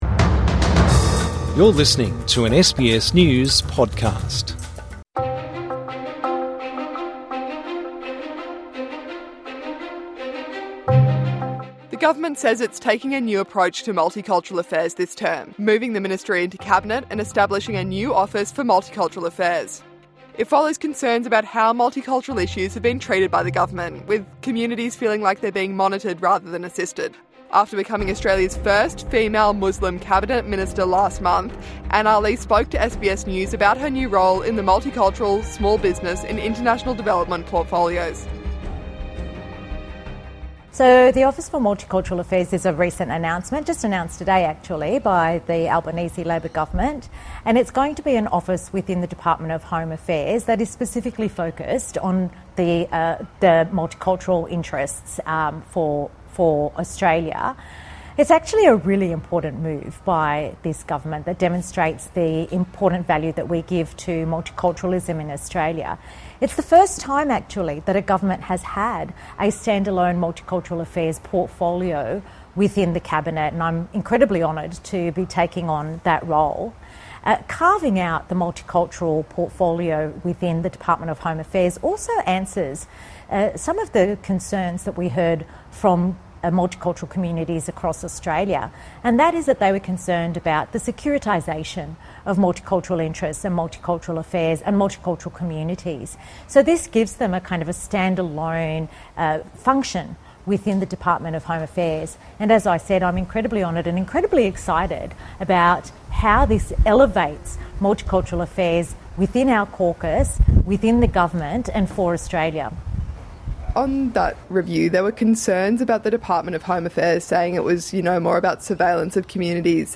INTERVIEW: Anne Aly on Australia's multiculturalism